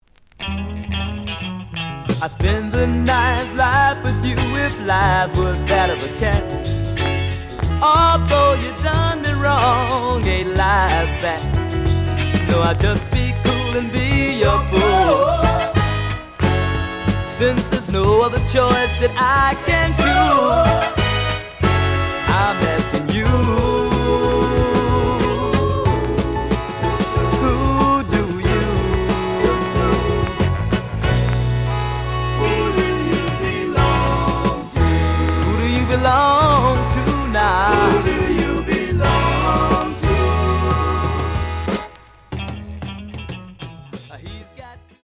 The audio sample is recorded from the actual item.
●Genre: Soul, 70's Soul
Slight damage on both side labels. Plays good.)